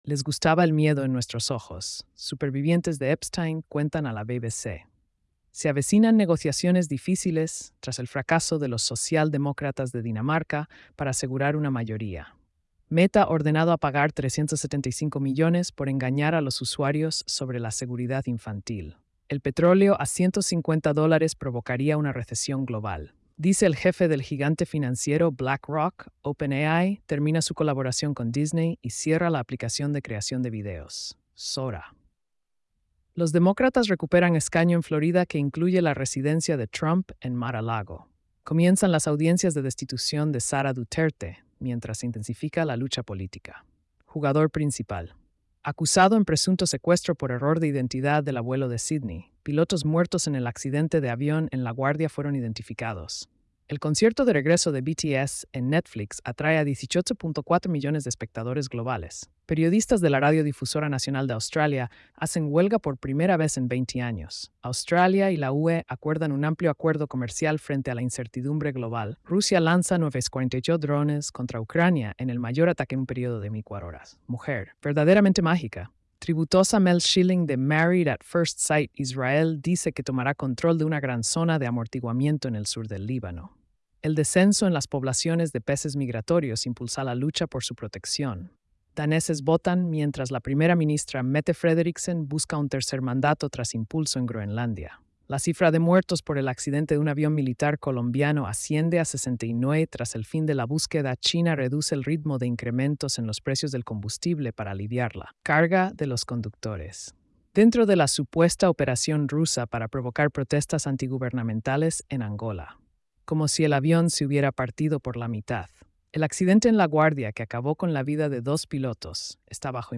🎧 Resumen de noticias diarias. |